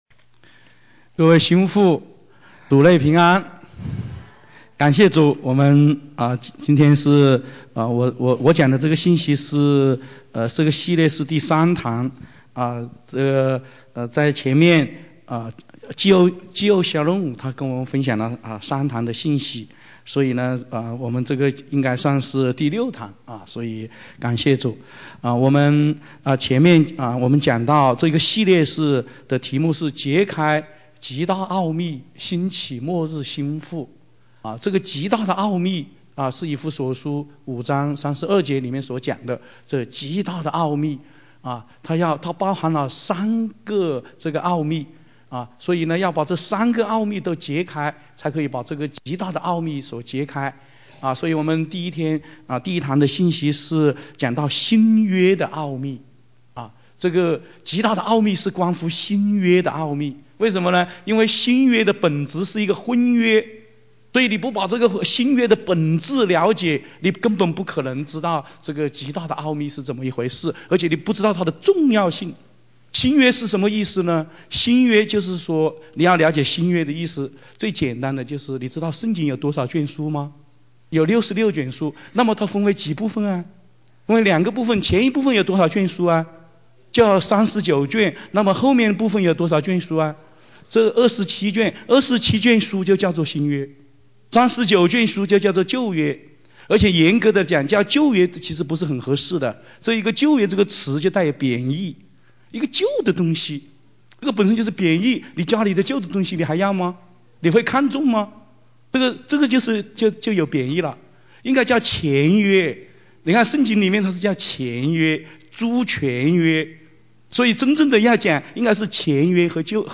2017温哥华国际新妇特会